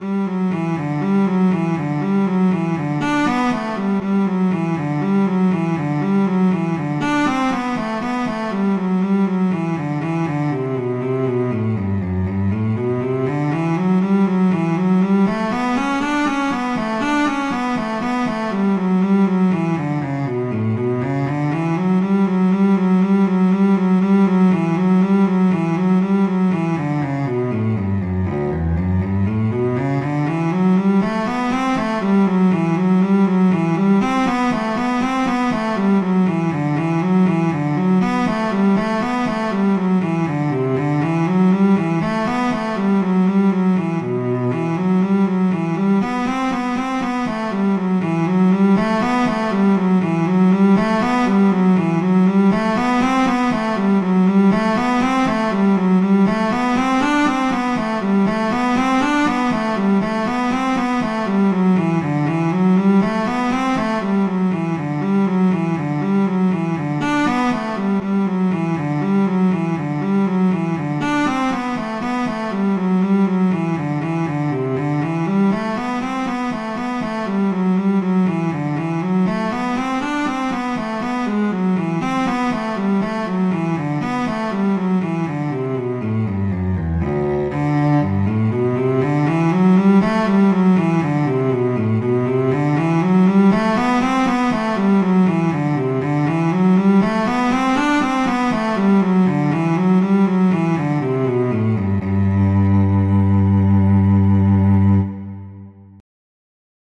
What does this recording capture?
classical, instructional